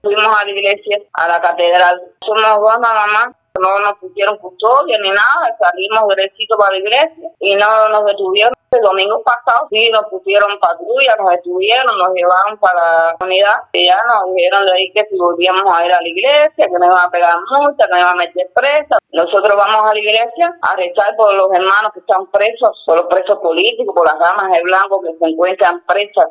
Delcaraciones